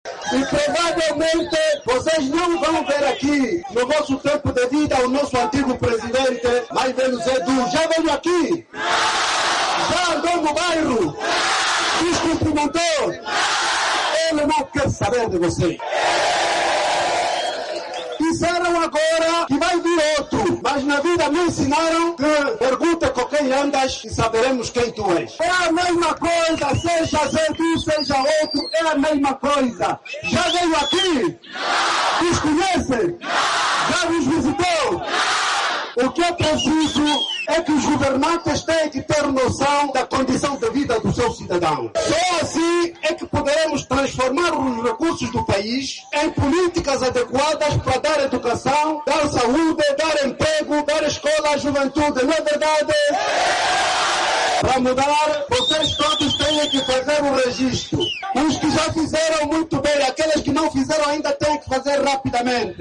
“Ele não quer saber de vocês”, disse o líder da CASA-CE que falava no fim-de-semana em Negage, na província do Uíge, e onde apelou aos eleitores para se registarem e garantir a mudança
Abel Chivukuvuku fala no Uíge - 1:02